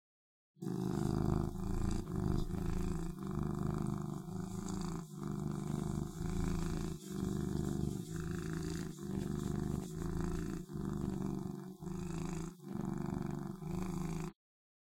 Cat Purring Sound Button - Free Download & Play
Cat Sounds136 views